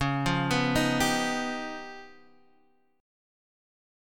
Listen to C#9b5 strummed